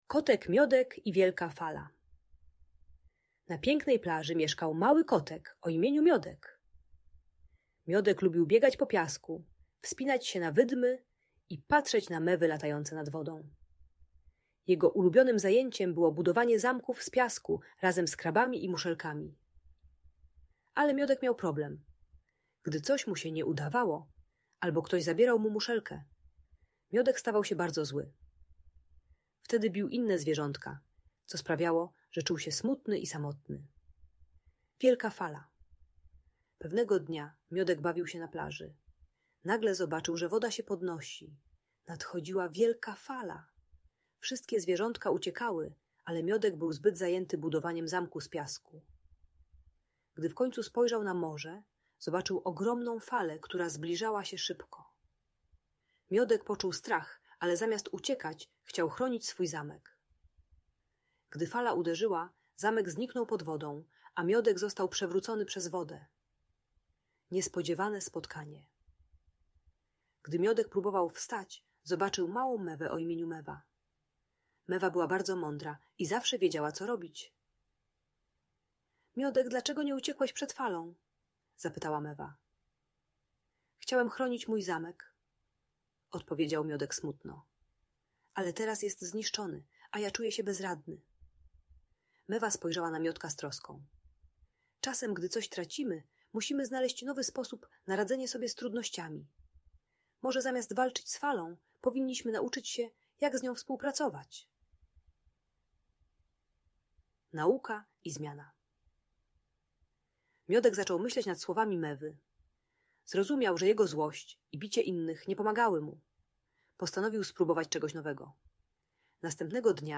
Kotek Miodek i jego historia pełna przygód - Audiobajka dla dzieci